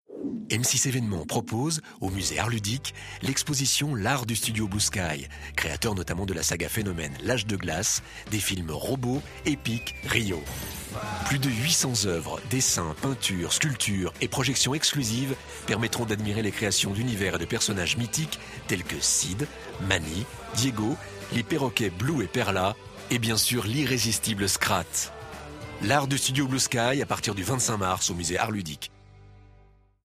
Genre : Voix-off